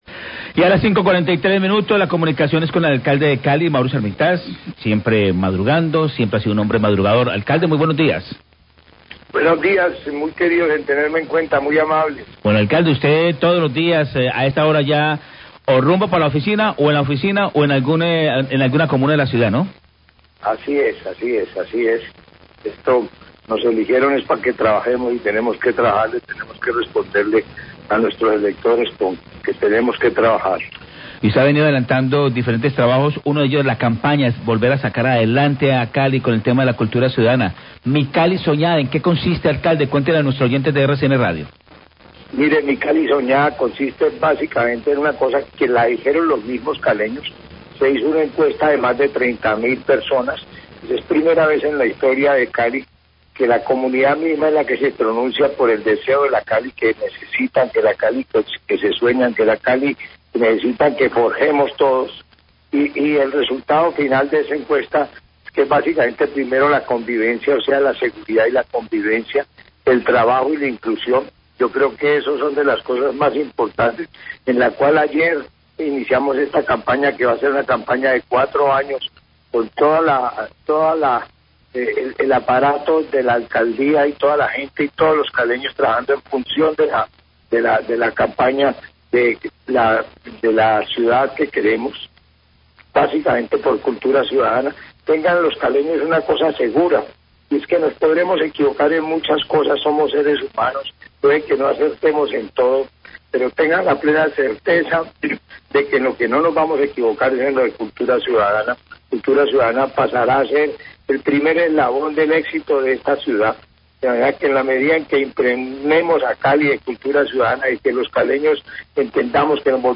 ENTREVISTA A ALCALDE ARMITAGE SOBRE MI CALI SOÑADA, 5.43AM
Entrevista al Alcalde, Maurice Armitage, sobre el lanzamiento de la campaña de cultura ciudadana Mi Cali Soñada, como resultado de la encuesta realizada en Cali sobre la ciudad con la que sueñan los caleños. También habla sobre los cambios en el mantenimiento de las zonas verdes.